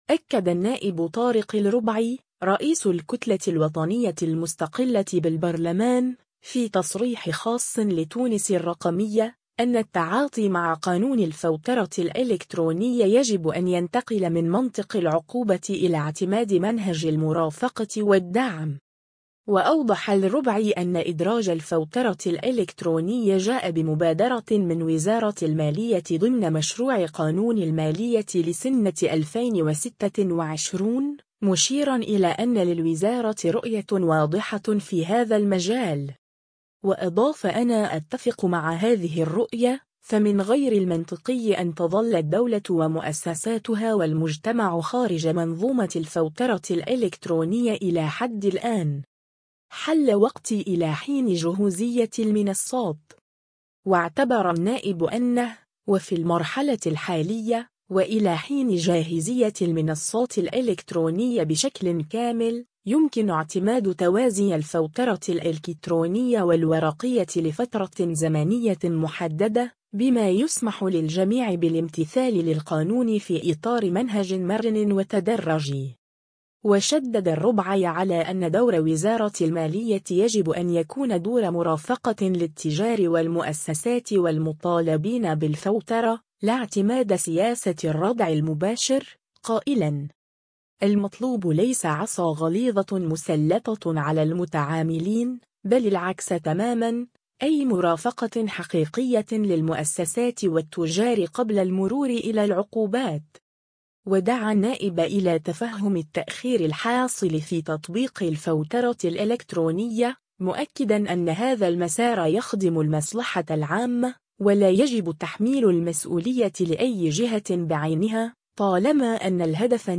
أكد النائب طارق الربعي، رئيس الكتلة الوطنية المستقلة بالبرلمان، في تصريح خاص لـ“تونس الرقمية”، أن التعاطي مع قانون الفوترة الإلكترونية يجب أن ينتقل من منطق العقوبة إلى اعتماد منهج المرافقة والدعم.